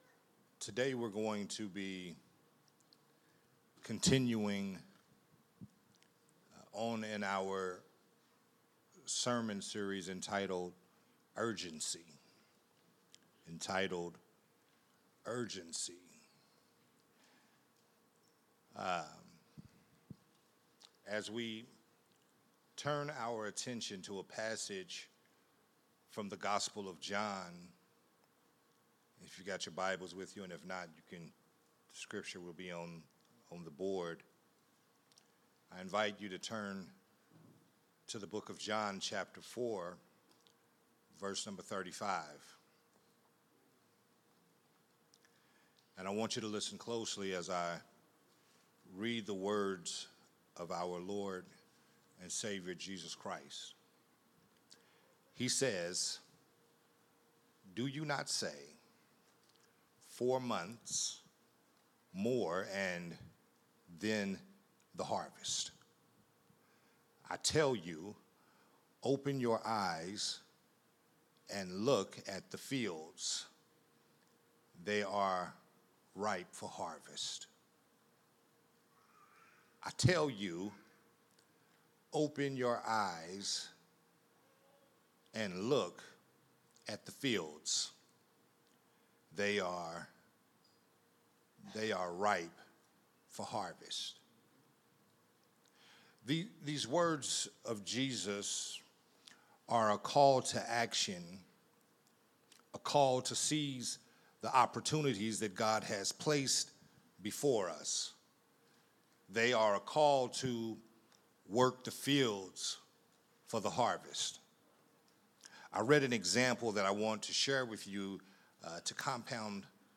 Urgency: Time is of the Essence Growth Temple Ministries